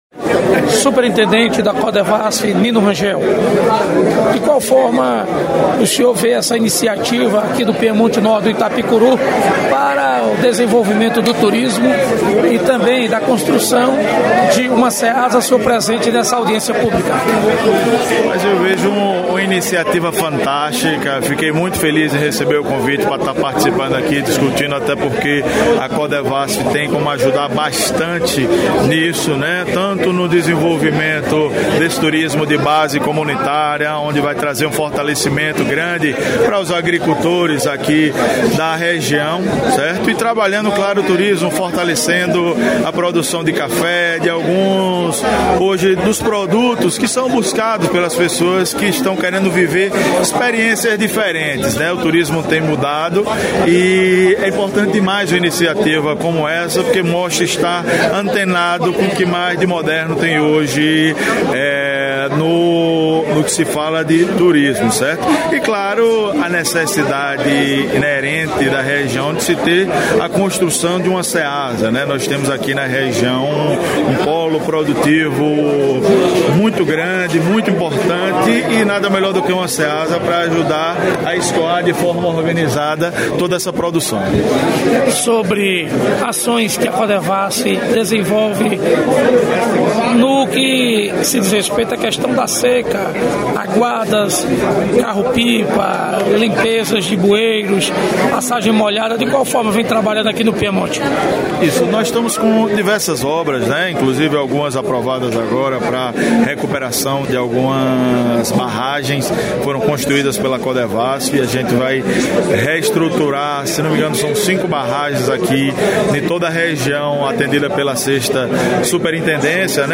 Reportagem: Reunião em Sr. do Bonfim com representantes do dos municípios sobre a implantação do turismo agroecológico e o Ceasa regional.